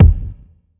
Kick SwaggedOut 3.wav